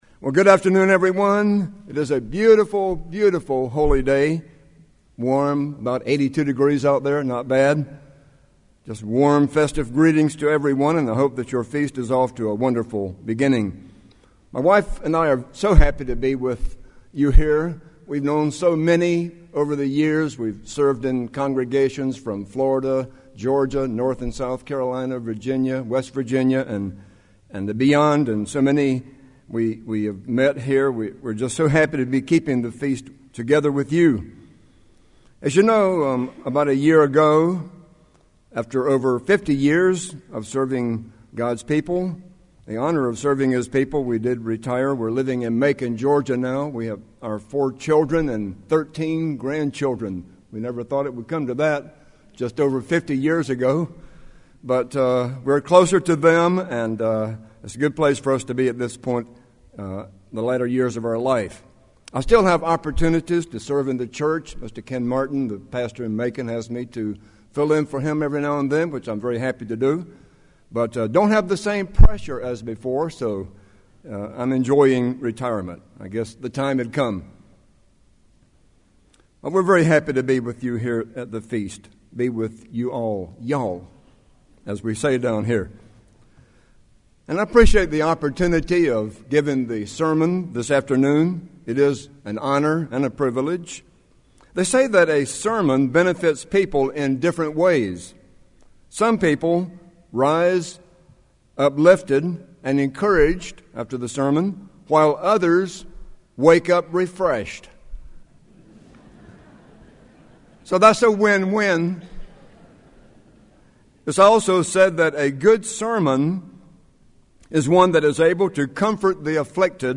This sermon was given at the Jekyll Island, Georgia 2016 Feast site.